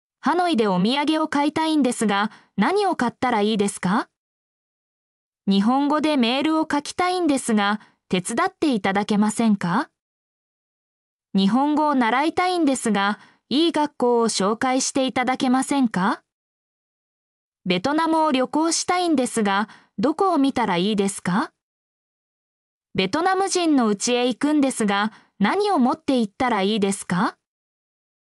mp3-output-ttsfreedotcom-17_Jx2km9rY.mp3